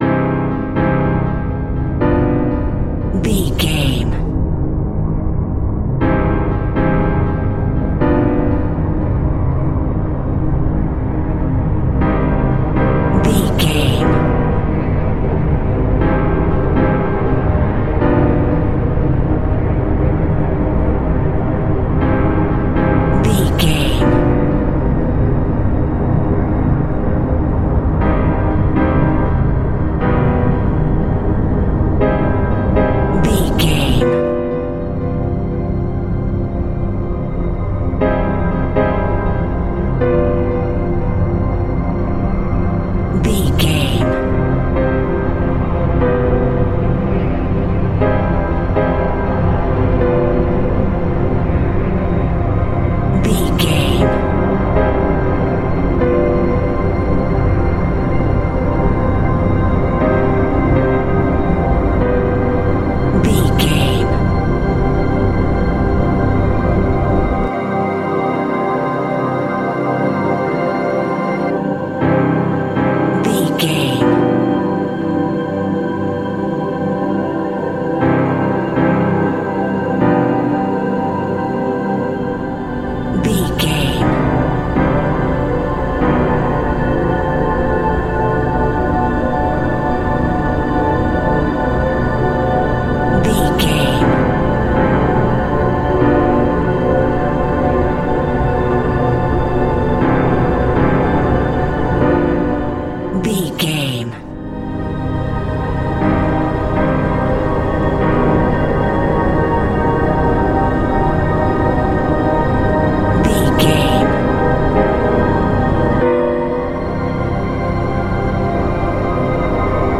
Thriller
Atonal
Slow
scary
tension
ominous
dark
suspense
haunting
strings
piano
creepy
synth
ambience
pads
electronic